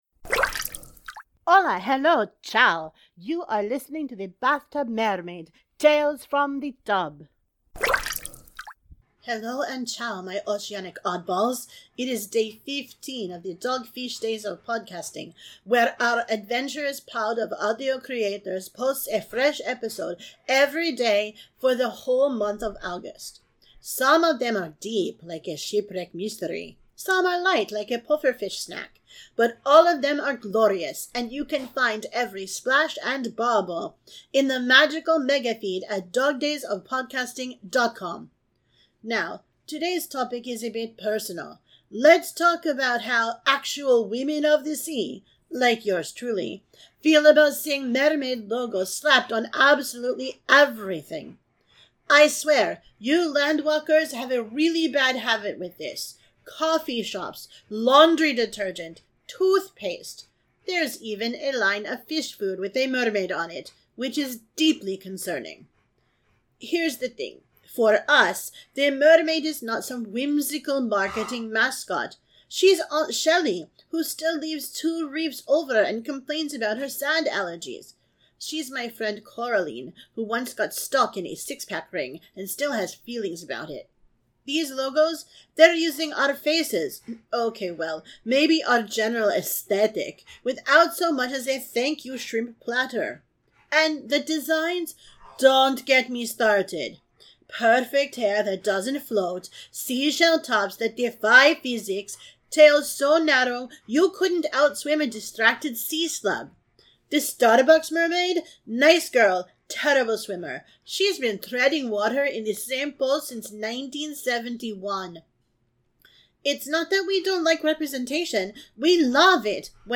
• Sound Effects are from Freesound.